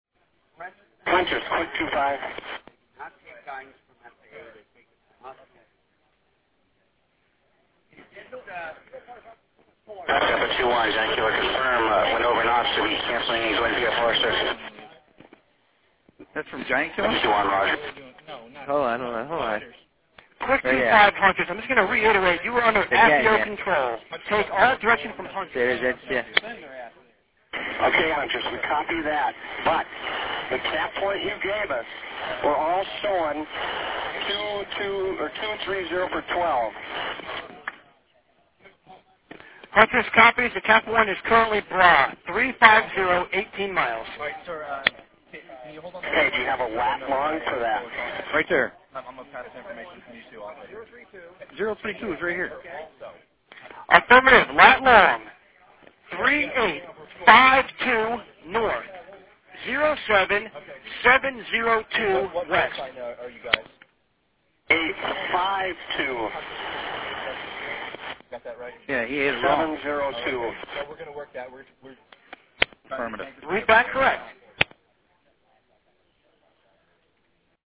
The following NEADS audio clip describes what happened at 0953 EDT just as the Quit flight was approaching the crossing point.
First, the Giant Killer reference was part of a radio transmission from the air, most likely from Team 21, a tanker being staged to support air defense activity.